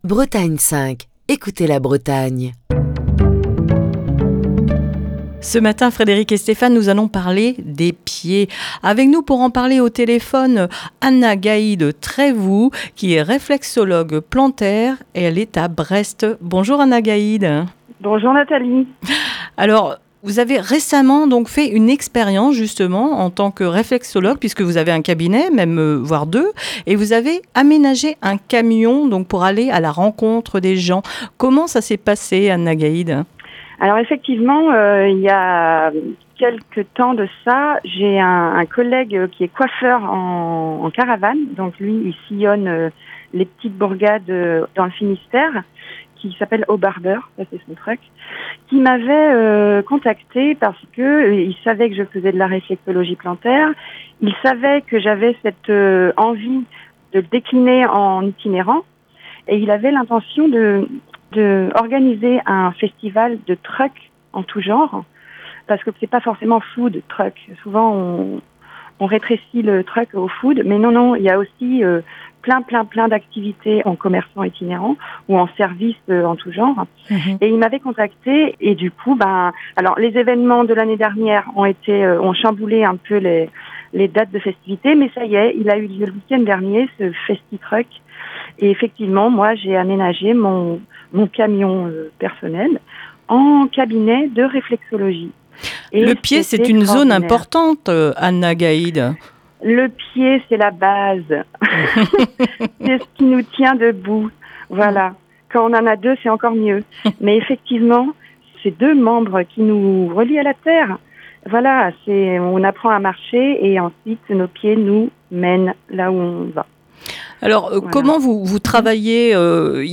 Ce lundi dans le coup de fil du matin